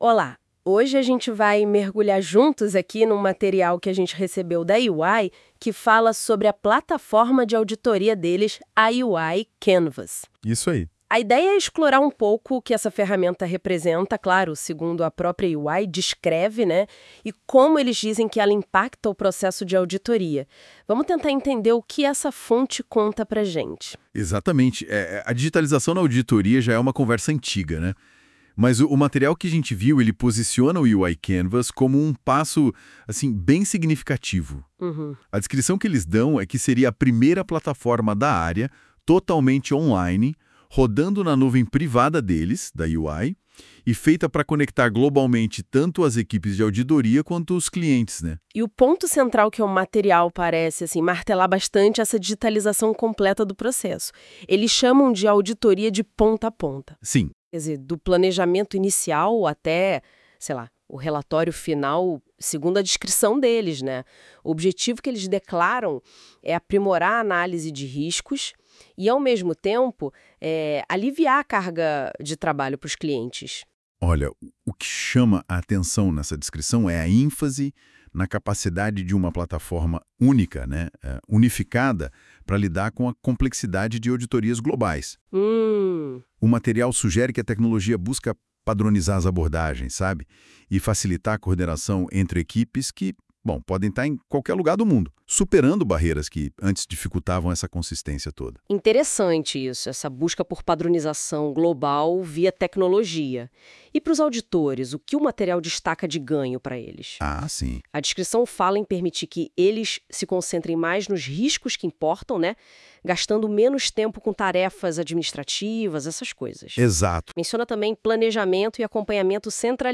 Também geramos um podcast com inteligência artificial, comentando as principais funcionalidades e vantagens da plataforma EY Canvas para o mercado de auditoria.